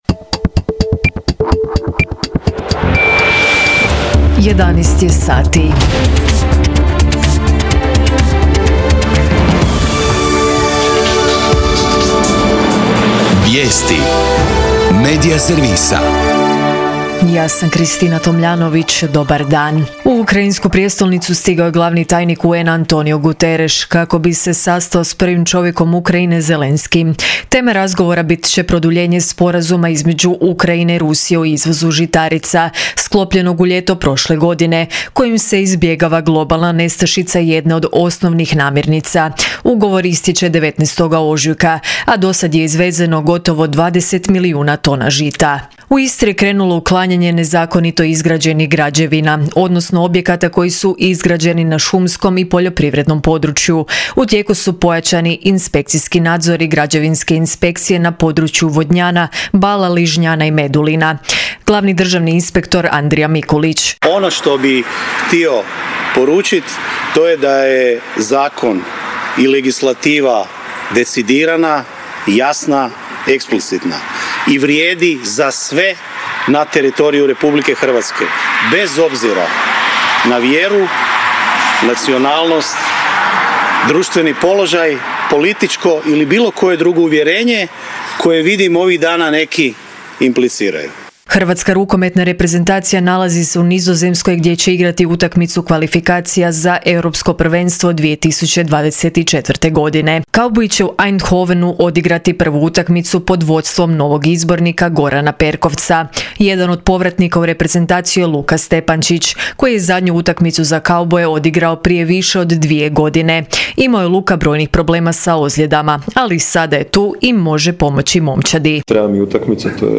VIJESTI U 11